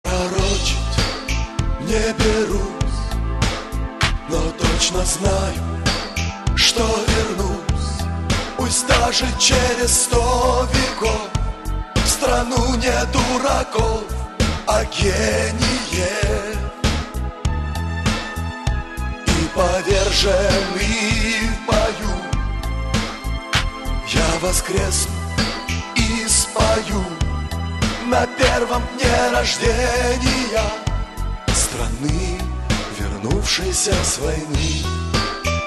Припев